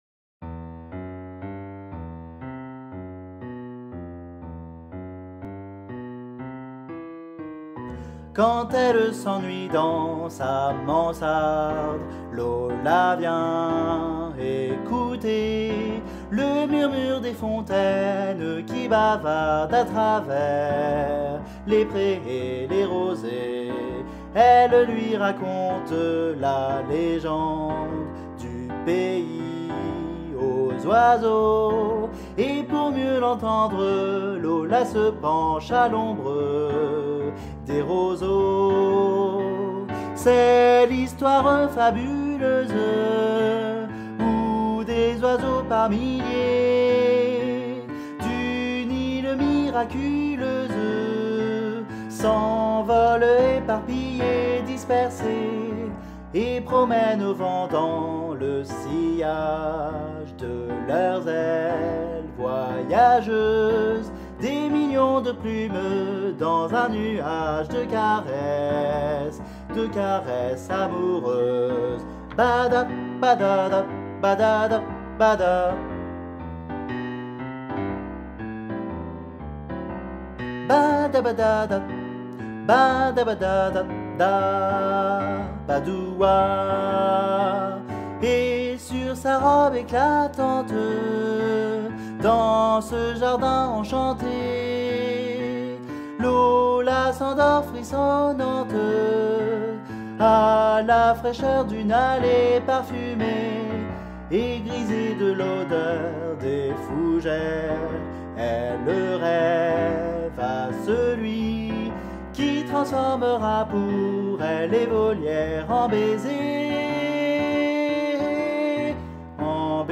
MP3 versions chantées
Guide Voix Sopranos